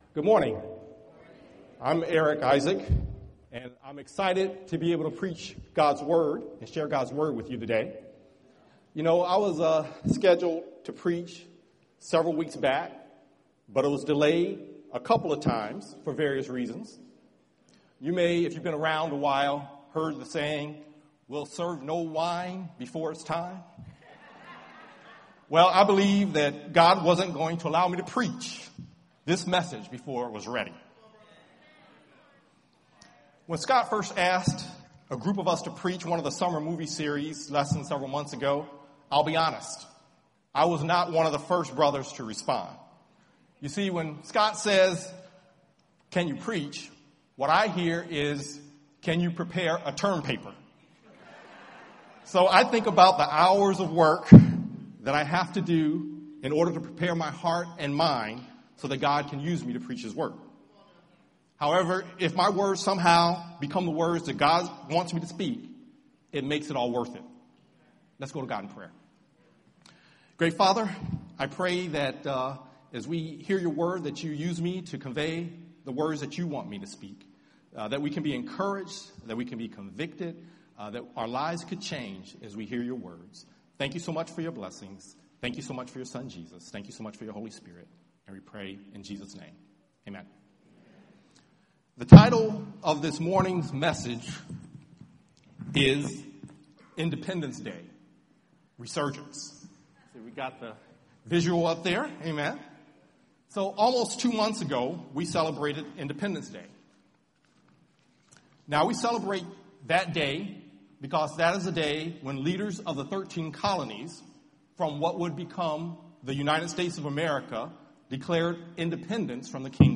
Sermon audio recordings from 2017